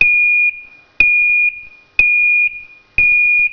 ZUMBADOR SONIDO INTERMITENTE
Sonido intermitente
Volumen regulable por medio de rejilla frontal giratoria
72 a 92dB
intermitente 230v.wav